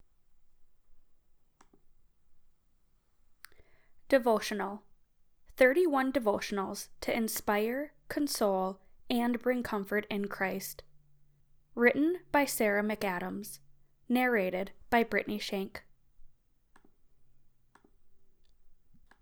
Special Interest Groups Audiobook Production
I am attaching audio both before and after I apply the filters.
I think Noise Reduction is distorting the tones in your voice before the other tools even get to it.
After I did that, the only thing I didn’t much like was the “essing” or frying SS sounds in your words.
Both Edited & unedited have a big bump in the spectrum at 2kHz …